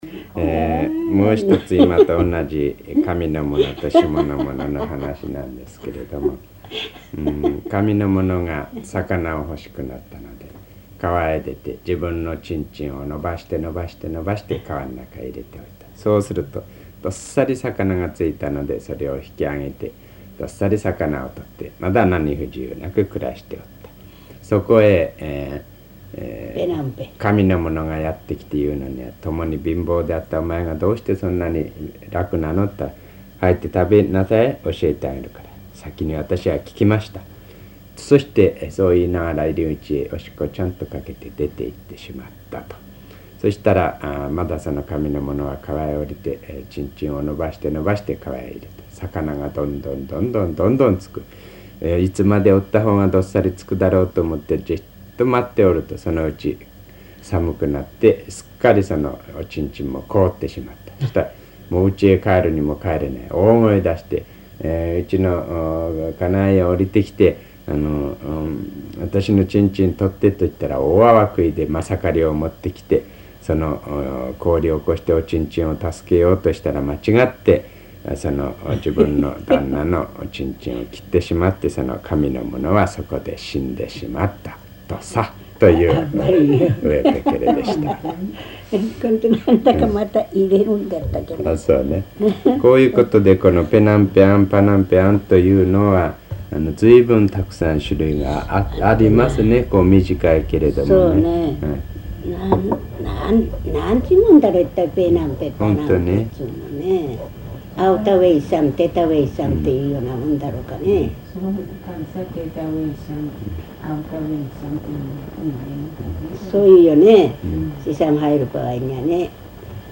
[8-11 解説 commentary] 日本語音声 2:14